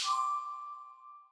soul pickup.wav